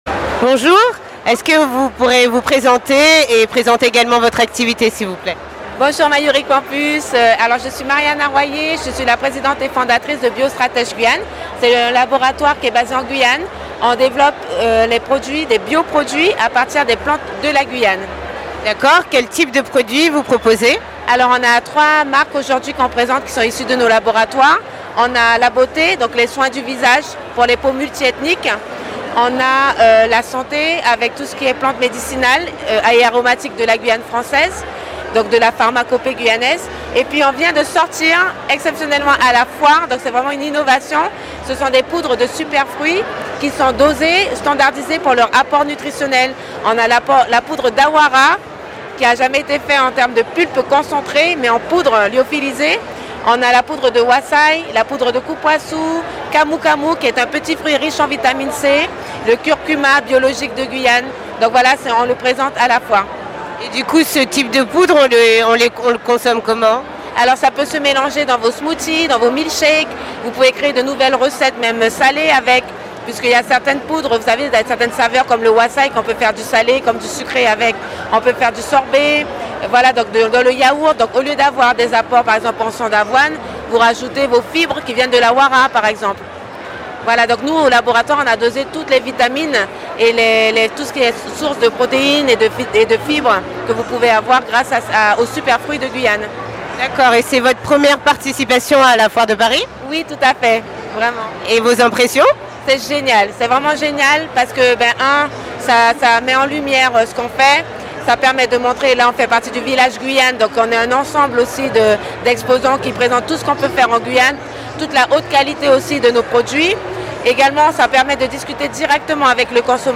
Nous l'avons rencontrée à la Foire de Paris, où elle participait pour la toute première fois.